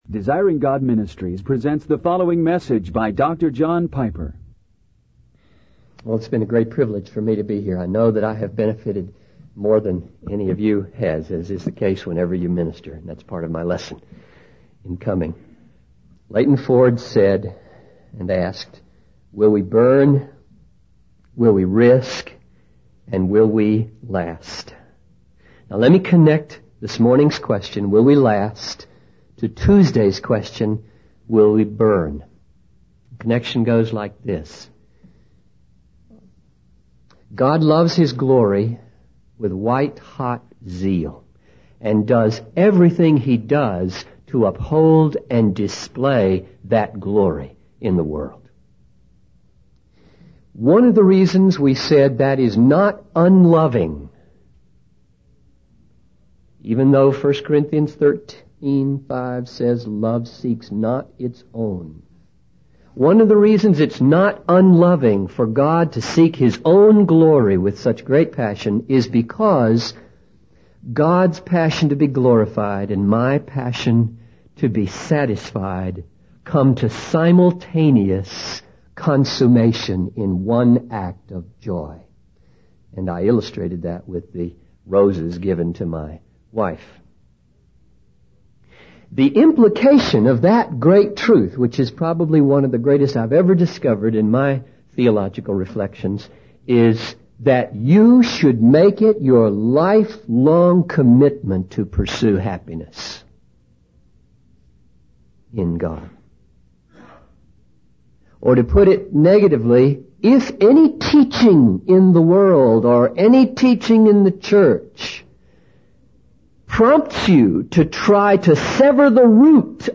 In this sermon, the speaker addresses the struggles and suffering that the audience has endured in the past.